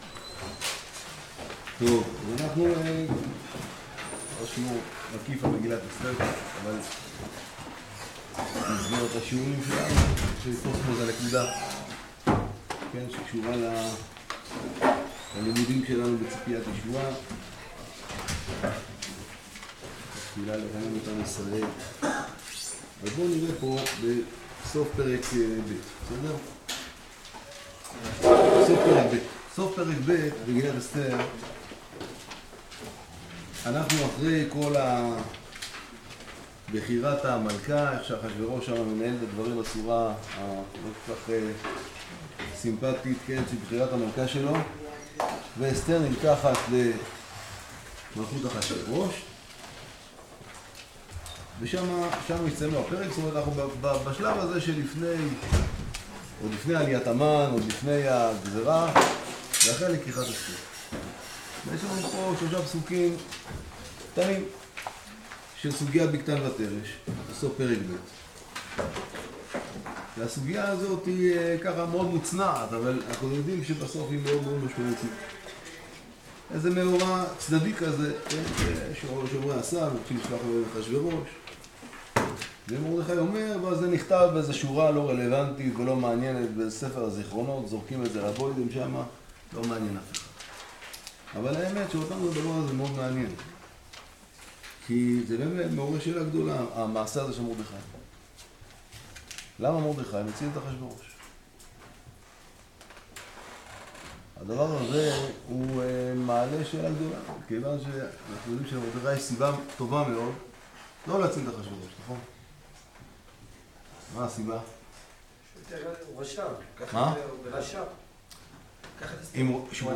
אתר ישיבת שבי חברון - ציפיית ישועה [20] | הציפייה שבמגילה - שיעור לפורים